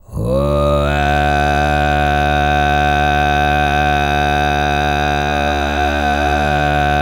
TUV5 DRONE05.wav